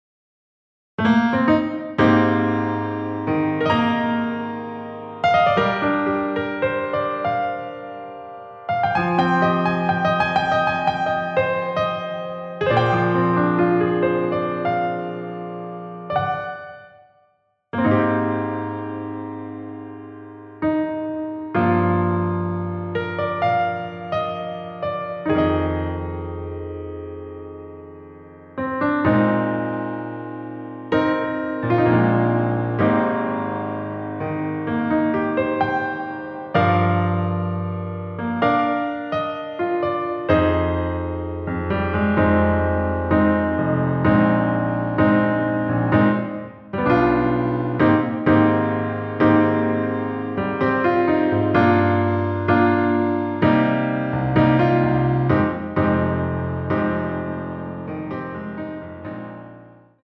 Eb
앞부분30초, 뒷부분30초씩 편집해서 올려 드리고 있습니다.
중간에 음이 끈어지고 다시 나오는 이유는